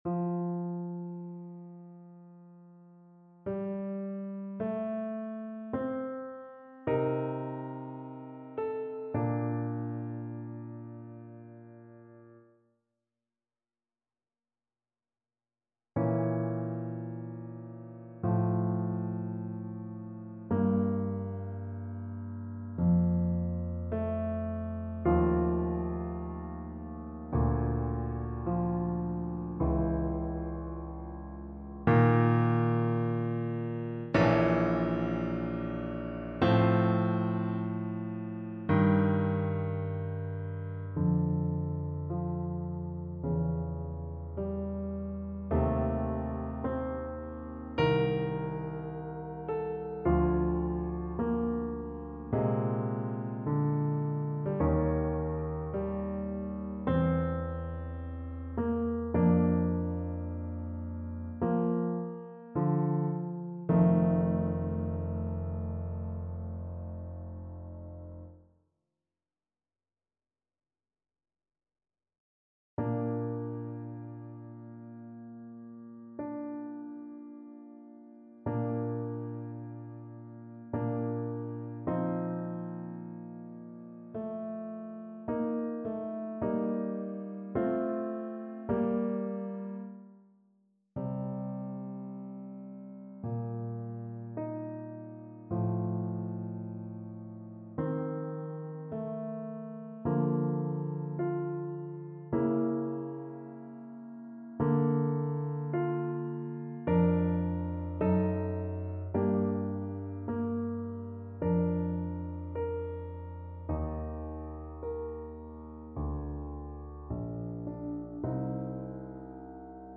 2/4 (View more 2/4 Music)
Larghetto =80
Classical (View more Classical French Horn Music)